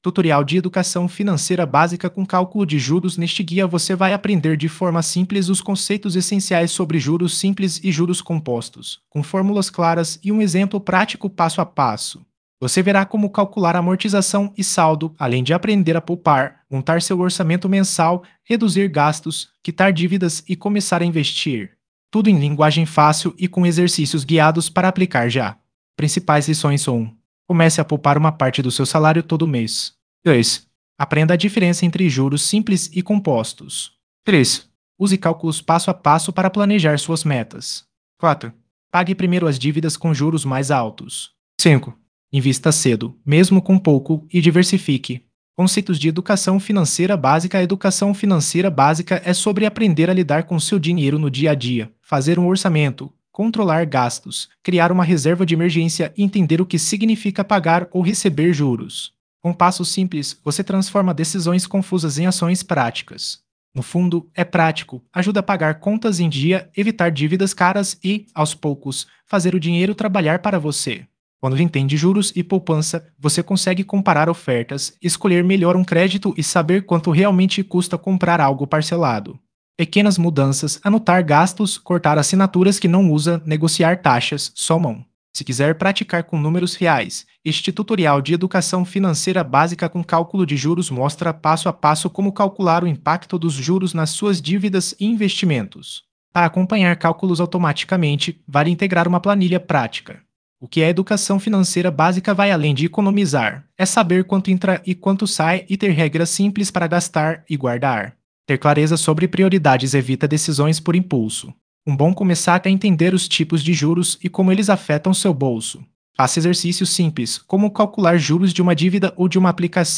Ouça O Artigo via Áudio (Acesso Simples & Rápido)